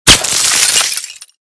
glass03hl.ogg